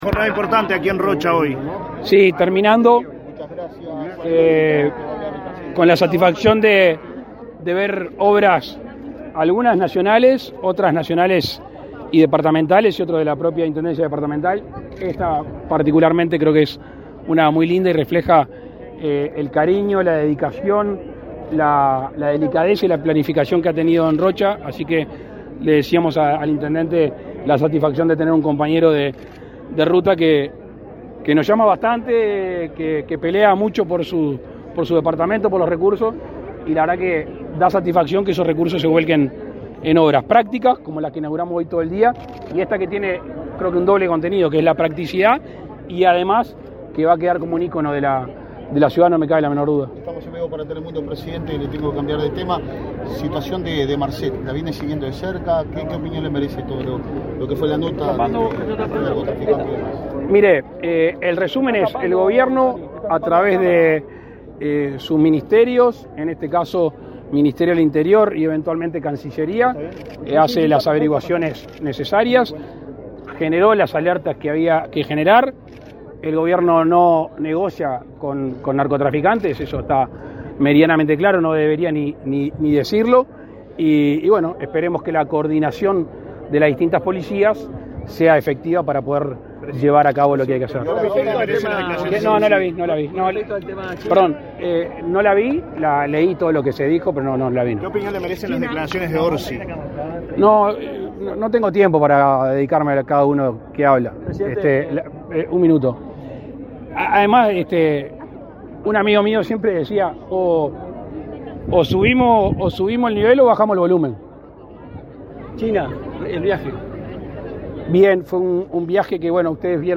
Declaraciones a la prensa del presidente de la República, Luis Lacalle Pou
Declaraciones a la prensa del presidente de la República, Luis Lacalle Pou 29/11/2023 Compartir Facebook X Copiar enlace WhatsApp LinkedIn Tras participar de la inauguración de obras en el puente Paso Real, en Rocha, último tramo del recorrido del presidente de la República, Luis Lacalle Pou, por la ciudad en el marco de sus 230 años de fundación, este 28 de noviembre, el mandatario realizó declaraciones a la prensa.